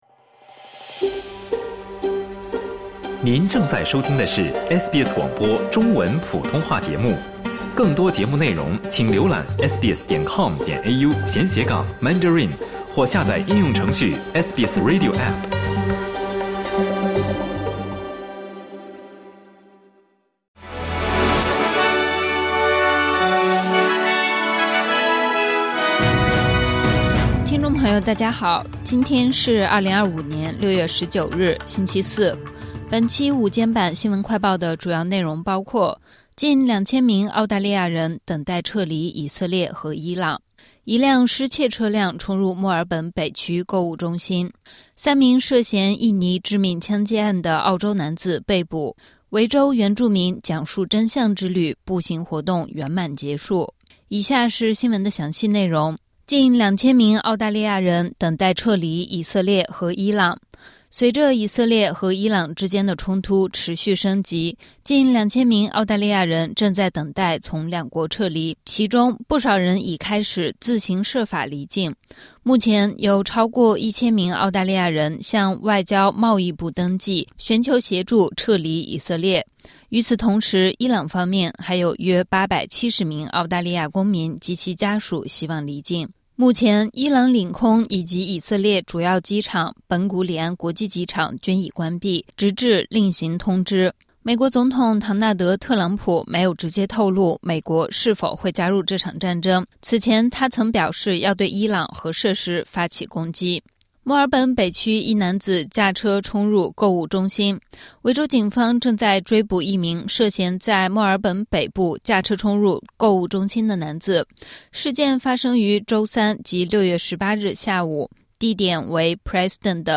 【SBS新闻快报】近两千名澳大利亚人等待撤离以色列和伊朗